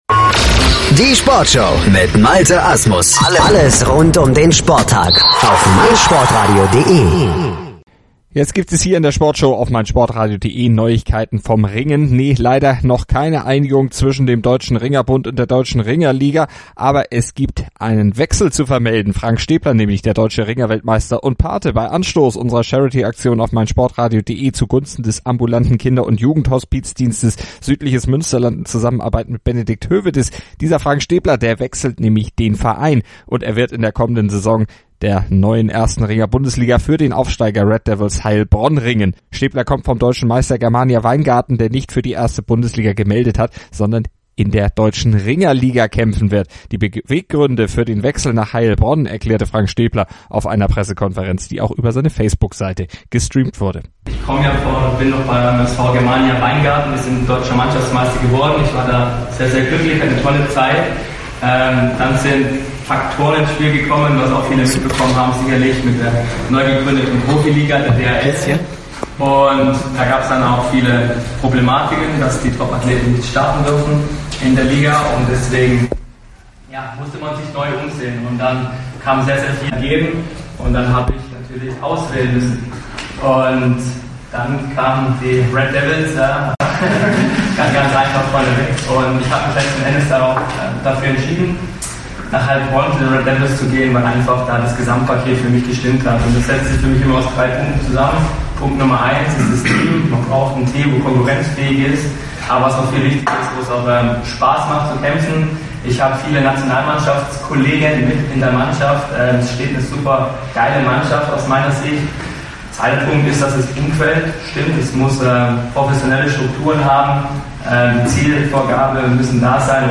einer Pressekonferenz erklärt er seinen Wechsel, übt Kritik an